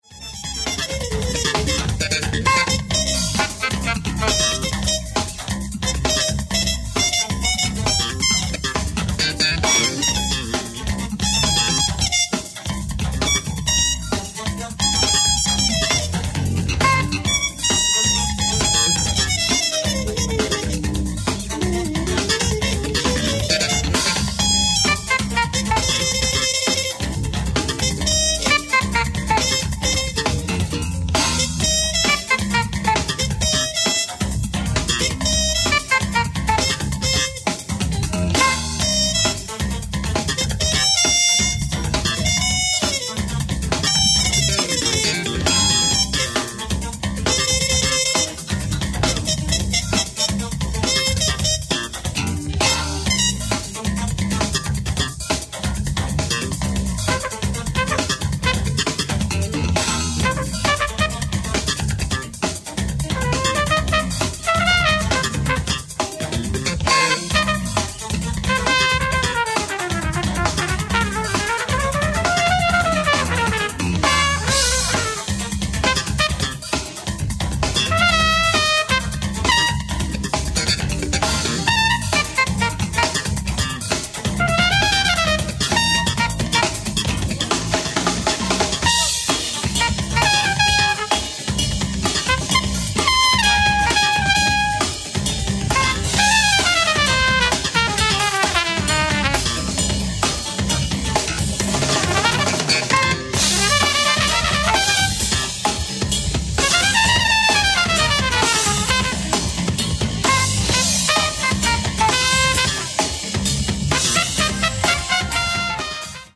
Live At Hohentwiel, Singen, Germany July 14, 1990
COMPLETE SOUNDBORAD RECORDING